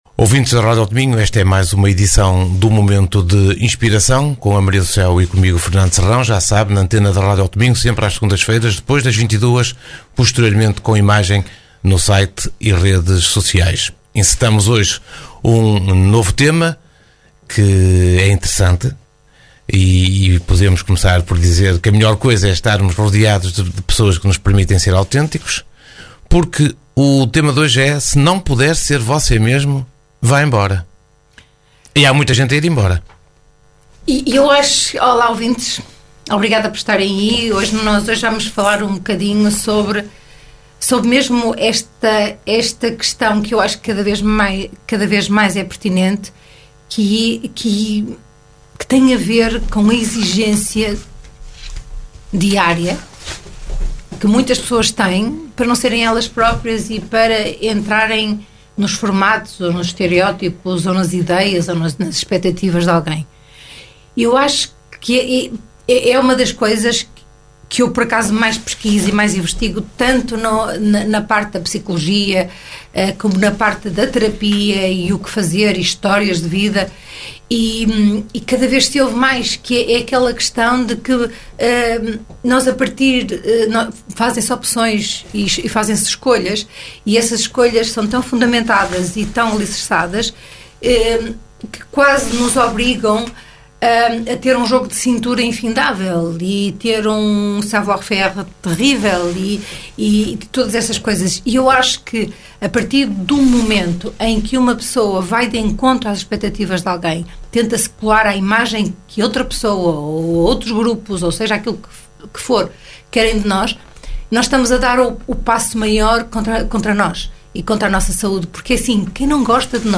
Momento de Inspiração Uma conversa a dois